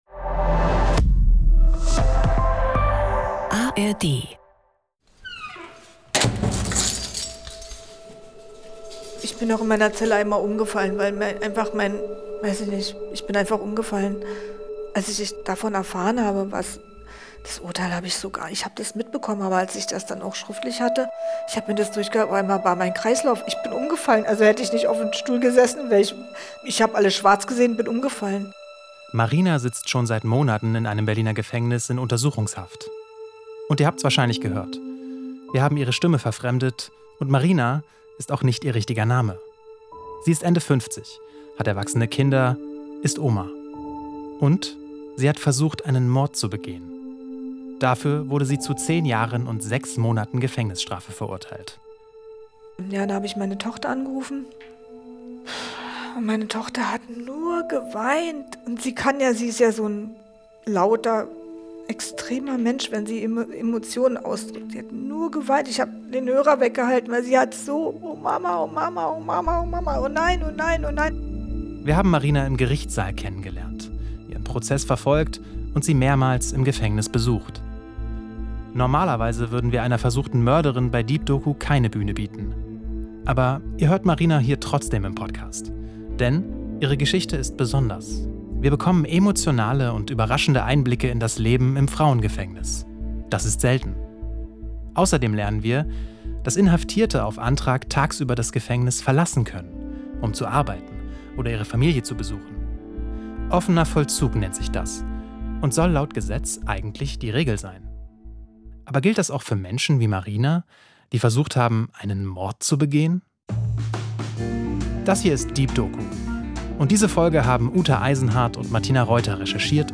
Deep Doku erzählt persönliche Geschichten und taucht alle zwei Wochen in eine andere Lebensrealität ein. Egal ob im Technoclub, der Notrufzentrale der Feuerwehr, auf einer Demo oder im Wohnzimmer – wir sind in unseren Audio-Dokus und Reportagen ganz nah dran.